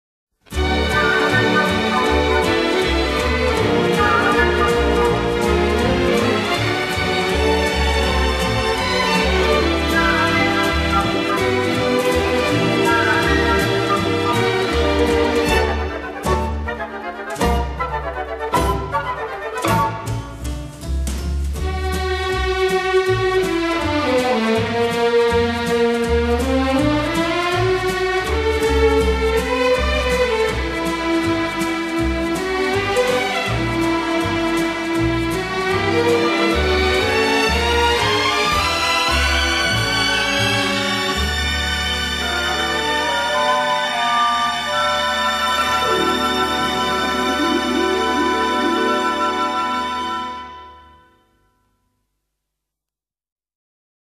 Einige dieser Tanzkompositionen sind auf dieser CD zu hören.